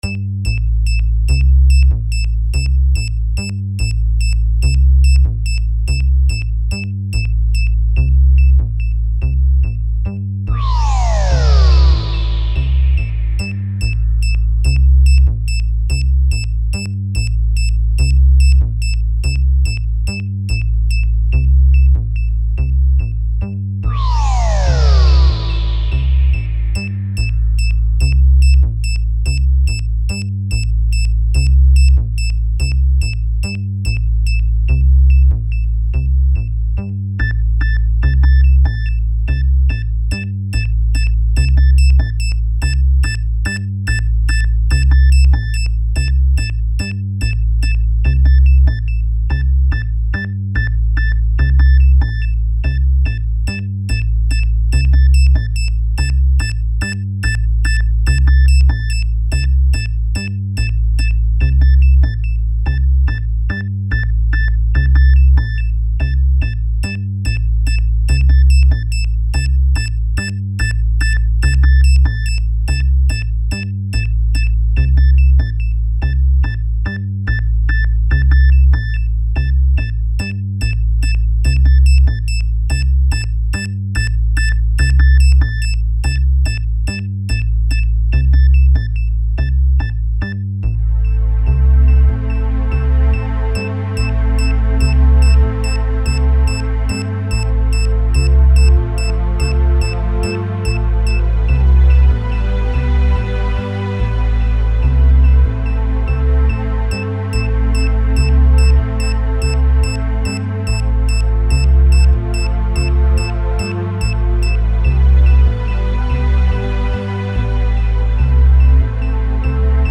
File under: Avantgarde
shifting around beats and rhythmic textures